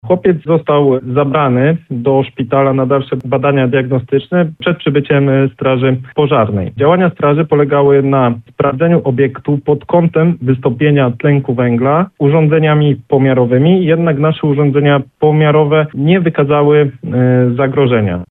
5strazak.mp3